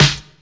• 1990s Sharp Hip-Hop Acoustic Snare Sample D Key 50.wav
Royality free steel snare drum tuned to the D note. Loudest frequency: 2370Hz